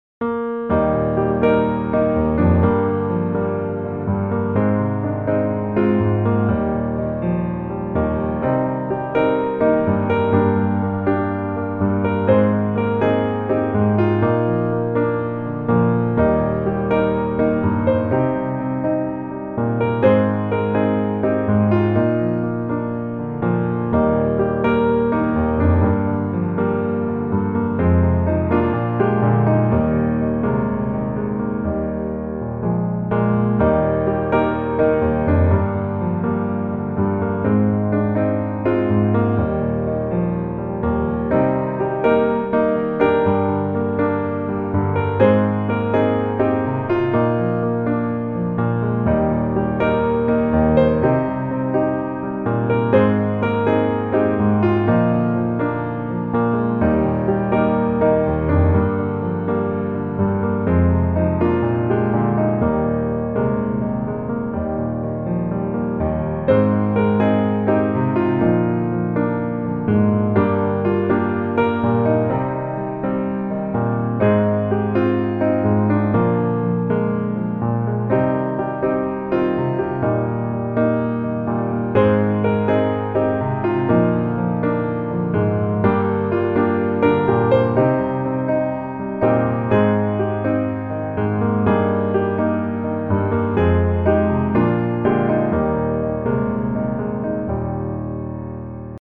Eb Major